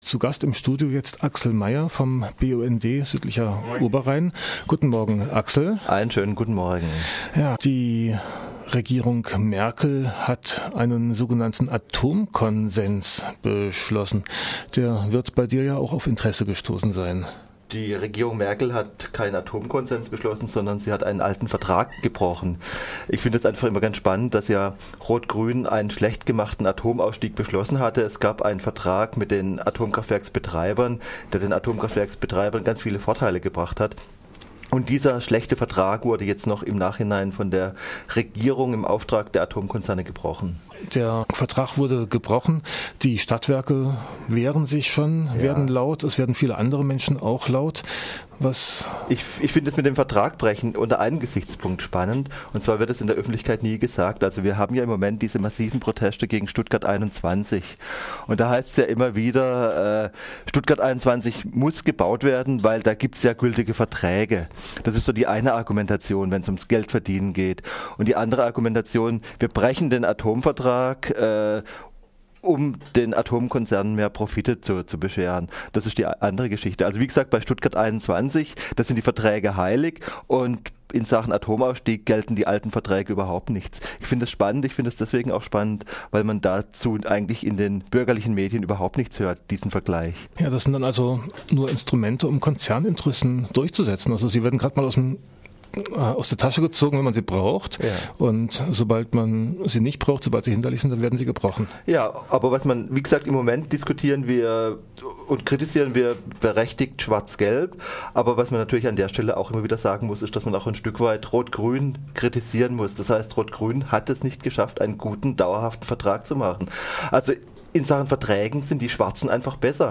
"Atomkonsens" bedeutet Vertragsbruch - Studiogespräch